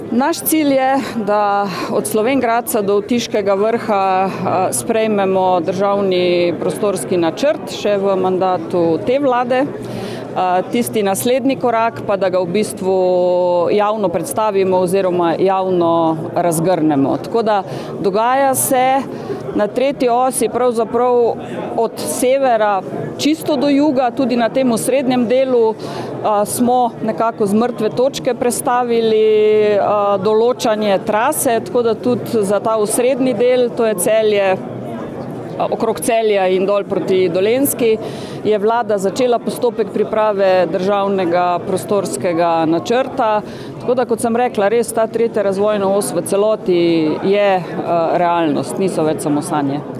Ministrica Alenka Bratušek: